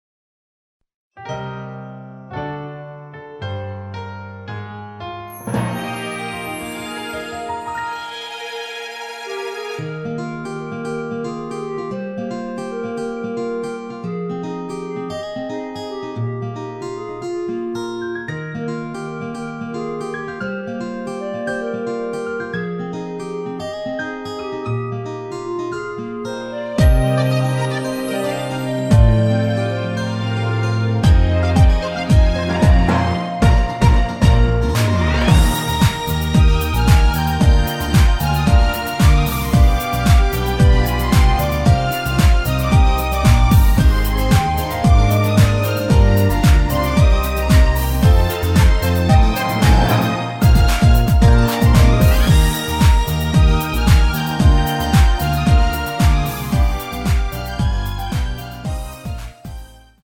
(+3)멜로디포함된 MR 입니다.(미리듣기 참조)
Eb
앞부분30초, 뒷부분30초씩 편집해서 올려 드리고 있습니다.
중간에 음이 끈어지고 다시 나오는 이유는